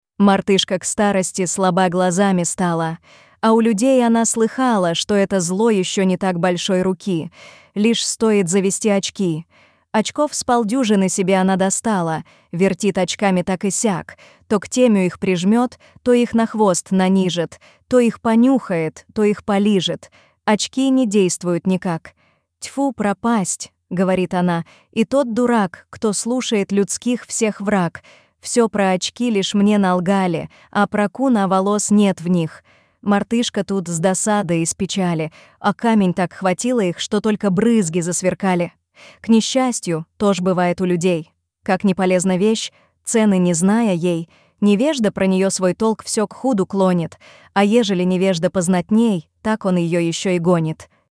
Эта увлекательная и поучительная история оживает благодаря выразительному исполнению.